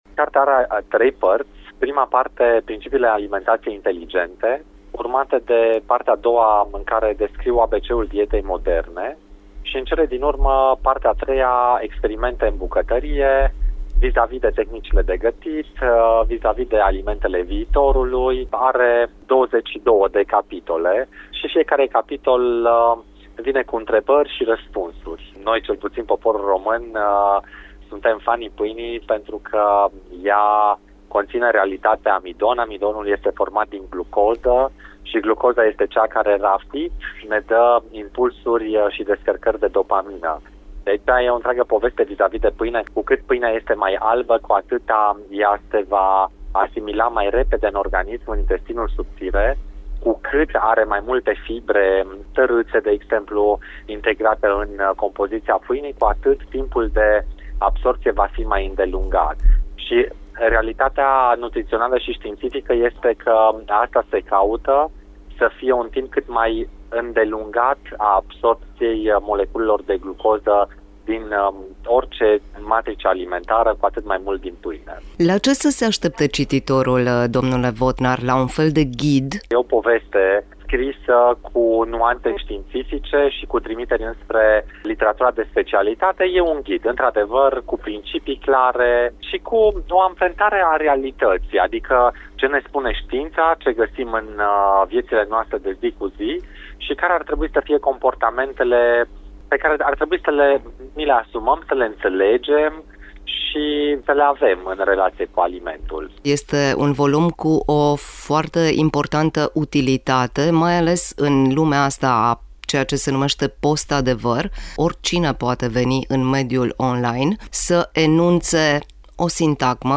Mai multe ”adevăruri din farfurie”, în discuția